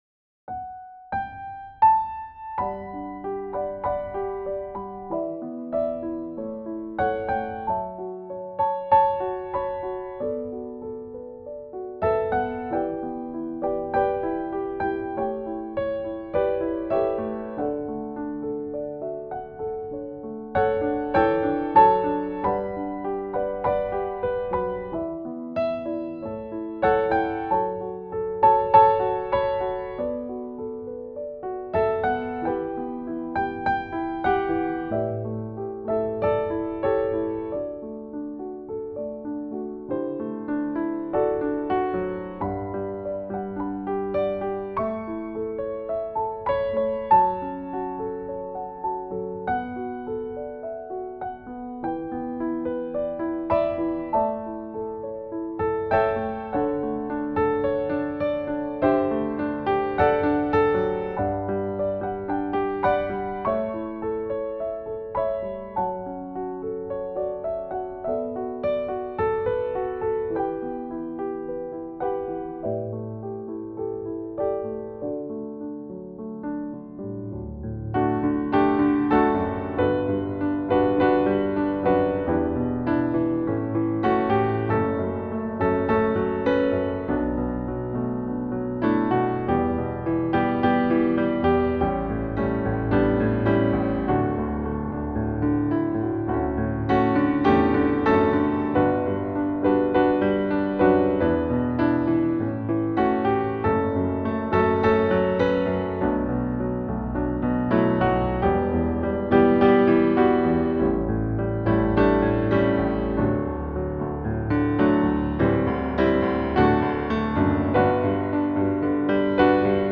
Praise Band At Home
Whilst we are unable to be together to play, the praise band and friends have been recording for the message each week.